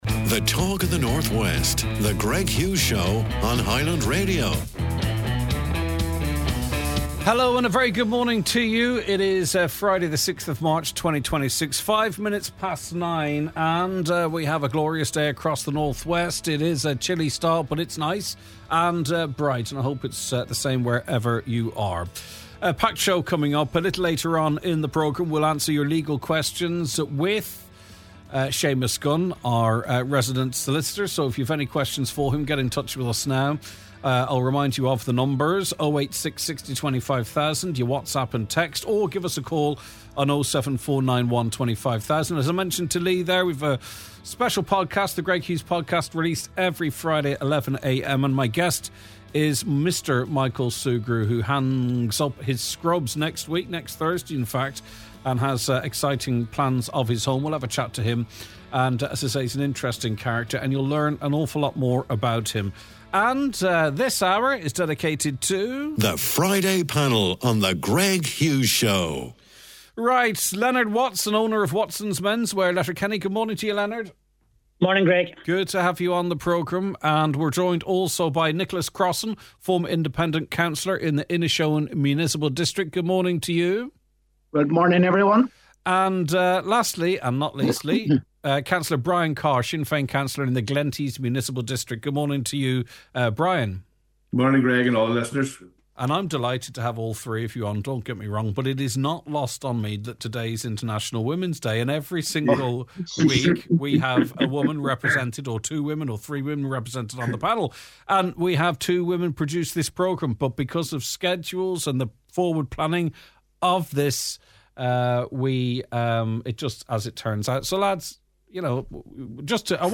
Listener Feedback: We wrap up the show by going through your comments and reactions to the week’s news.